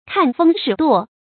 注音：ㄎㄢˋ ㄈㄥ ㄕㄧˇ ㄉㄨㄛˋ
看風使舵的讀法